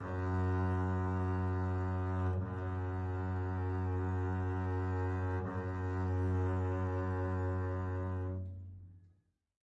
VSCO 2 CE 弦乐 低音提琴独奏 颤音延音 " 低音提琴独奏 颤音延音 F2 (BKCtbss Sus Vib F1 v3 rr1
标签： MIDI音符-42 字符串 单票据 MIDI-速度-95 颤音-维持 多重采样 低音提琴 独奏低音提琴 VSCO-2 F2
声道立体声